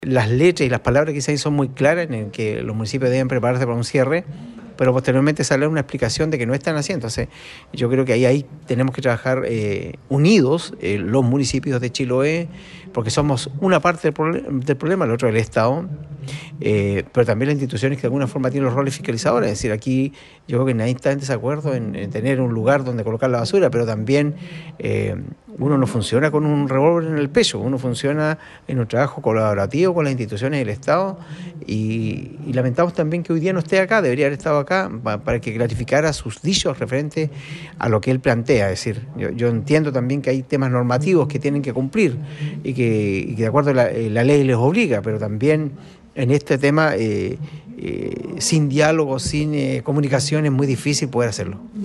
La emisión de un oficio por parte del seremi de Salud hace unas semanas, que hacía referencia al estado de los actuales vertederos en la provincia, también fue motivo para otras críticas por parte de las autoridades comunales, como lo expresó el edil de Quinchao, René Garcés.
03-ALCALDE-RENE-GARCES.mp3